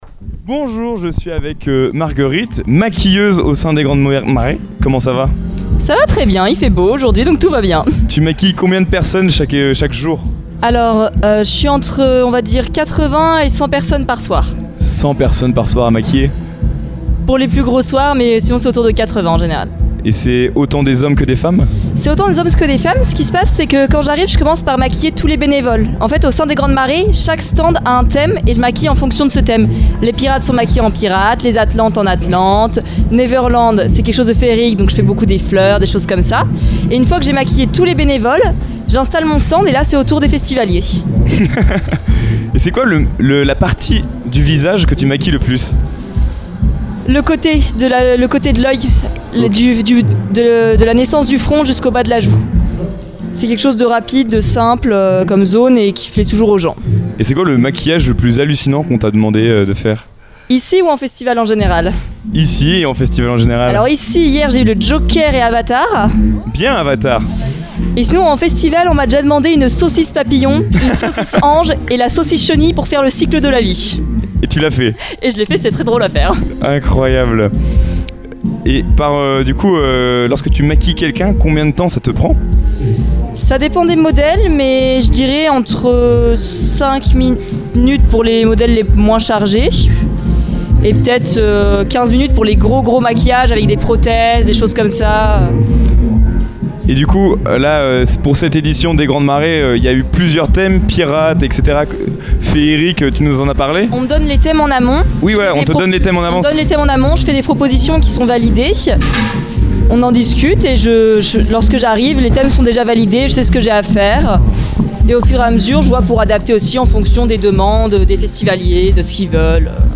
Une interview colorée et conviviale qui met en lumière l’importance des artistes et artisans qui contribuent à l’atmosphère unique du festival Les Grandes Marées.